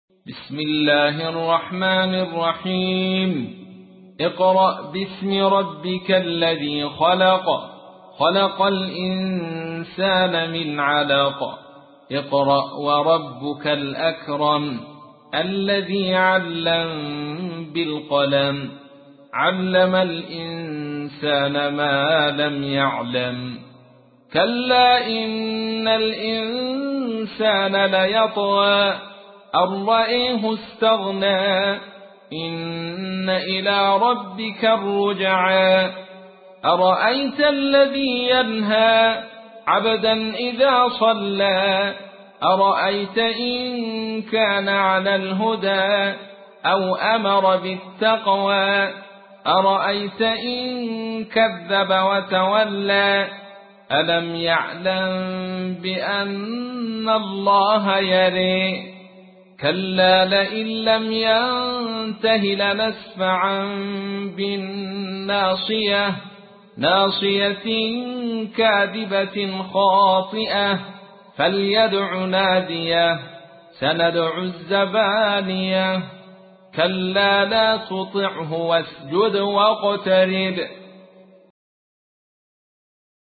تحميل : 96. سورة العلق / القارئ عبد الرشيد صوفي / القرآن الكريم / موقع يا حسين